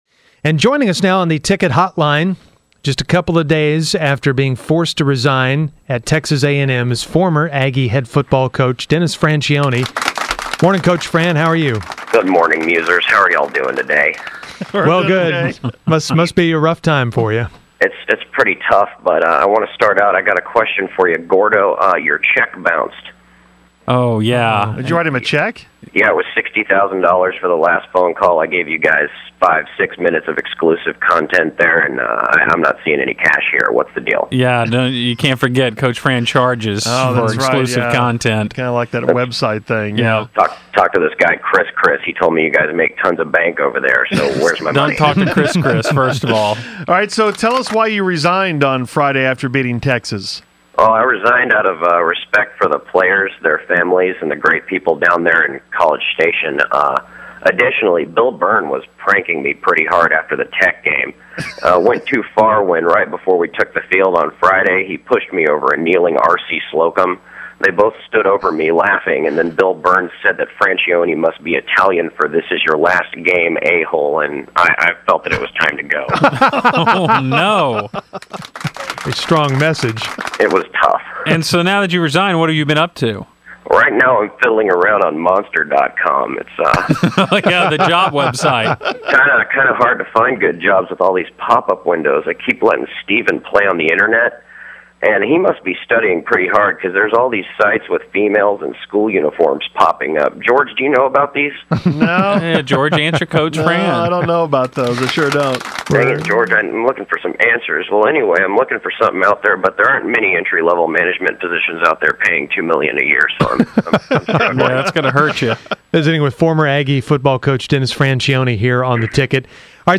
Category: Radio   Right: Personal
Tags: Ticket 1310 sports radio